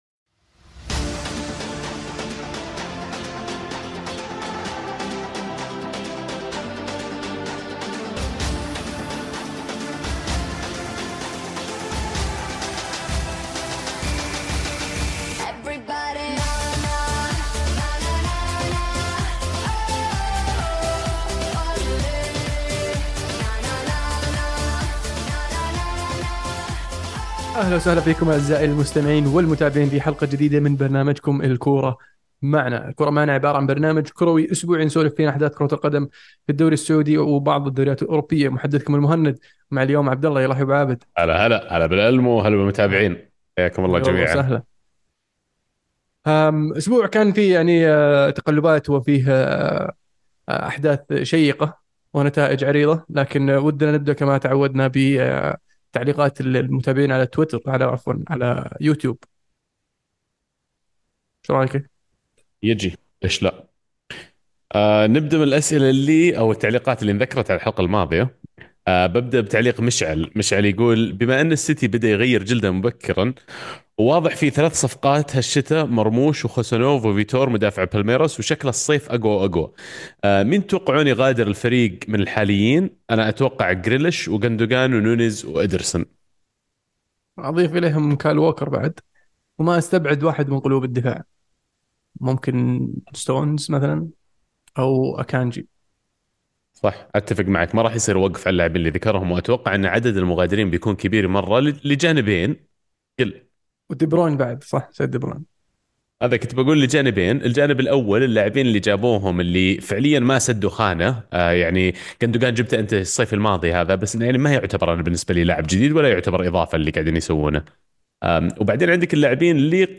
بودكاست "الكورة معنا" برنامج صوتي كروي اسبوعي من تقديم شباب عاشقين لكرة القدم، يناقشون فيه اهم الاحداث الكروية العالمية والمحلية خلال الأسبوع بعيد عن الرسمية.